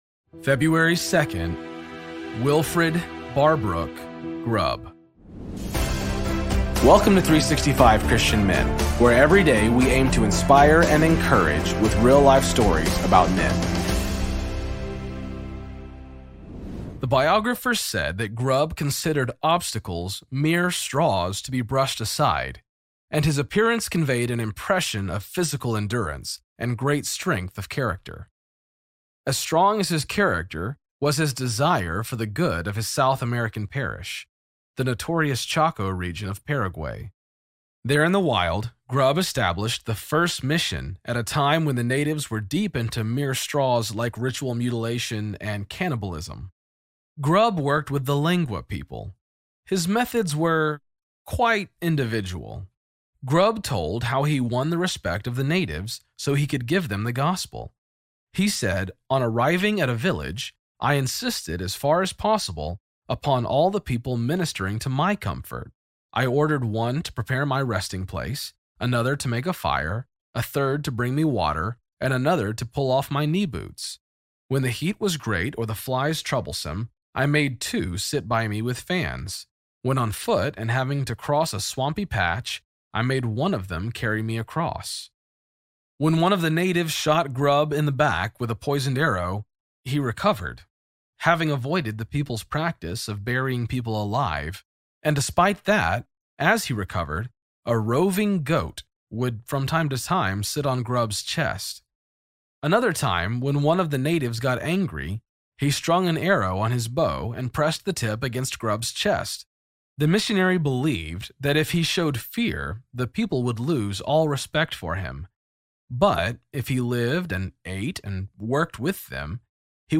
Story read